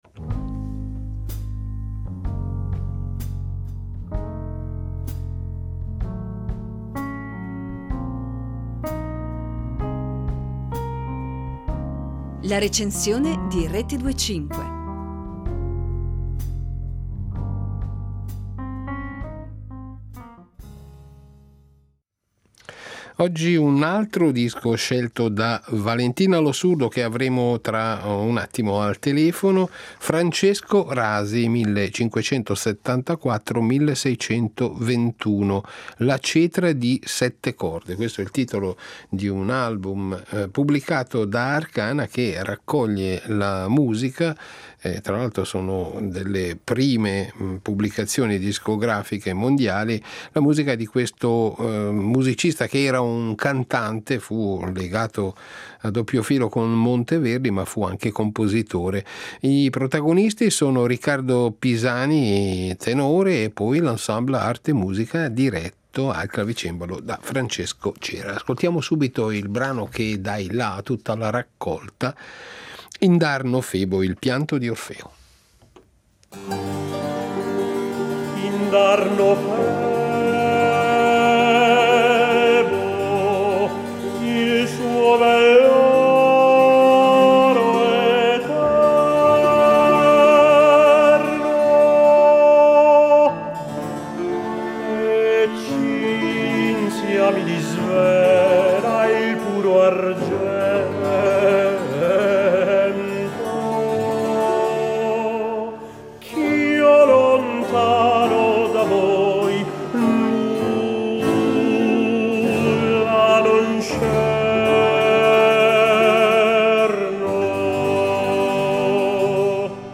Lo stile vocale di Rasi, pensato per la voce di tenore, è molto vicino al primo Monteverdi, caratterizzato da virtuosismi talvolta estesissimi, ma anche da una cantabilità piana ed elegante.